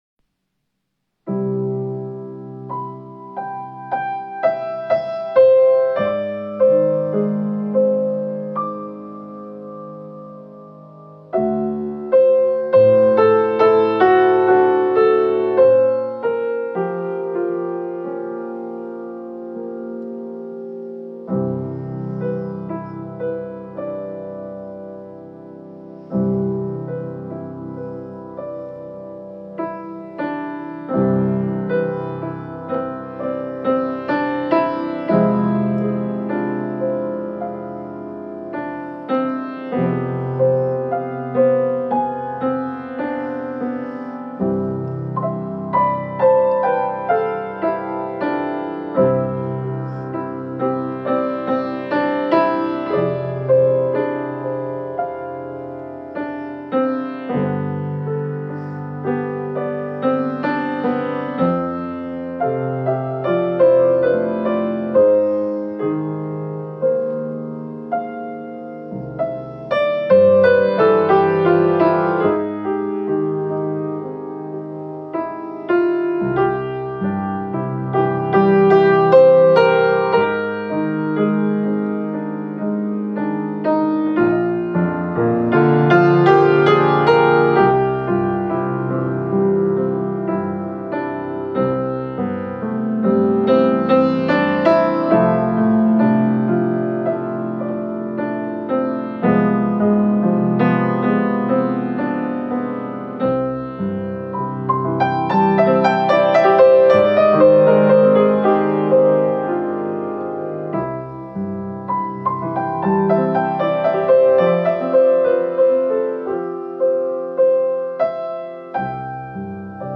Hymn arrangement medley